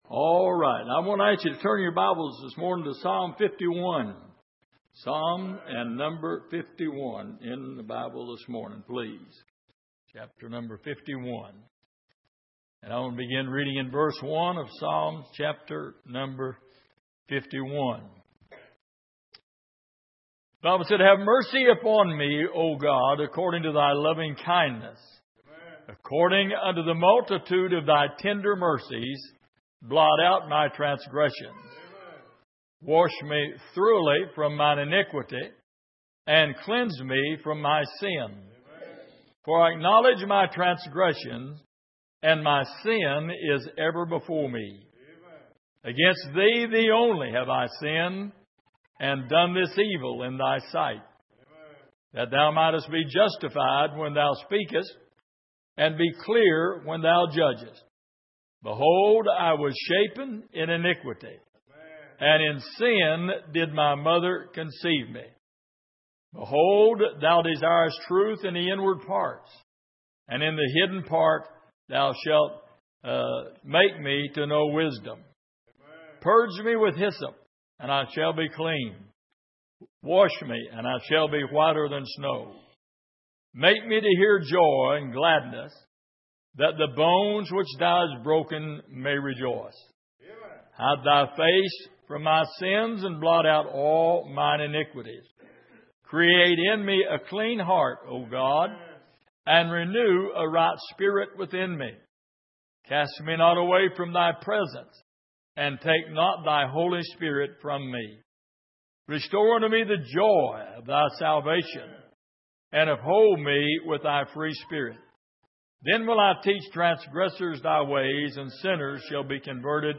Passage: Psalm 51:1-19 Service: Sunday Morning